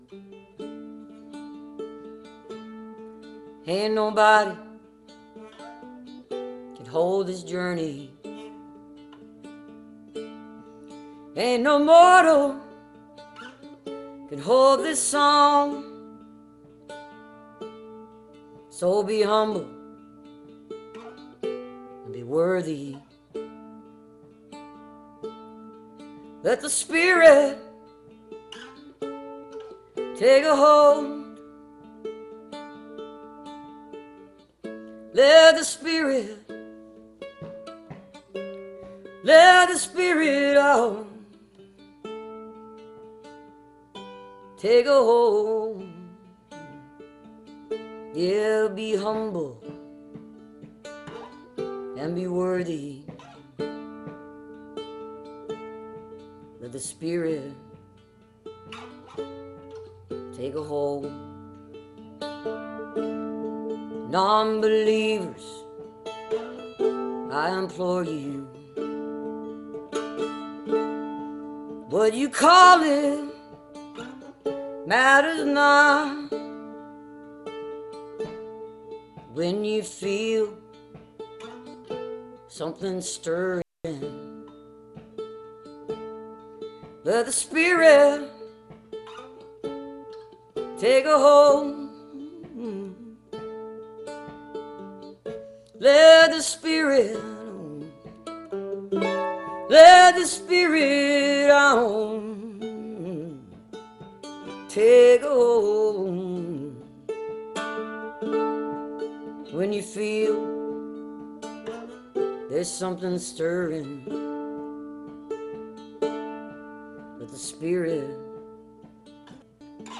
(captured from the live video stream)